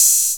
Open Hats
roland oh.wav